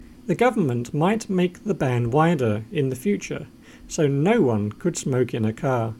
DICTATION 8